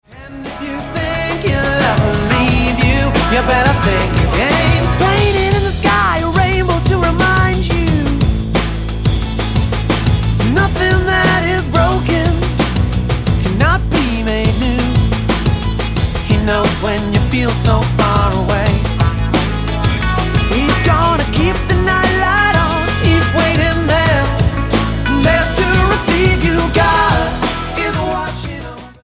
STYLE: Pop
features great harmonies and a lilting pop melody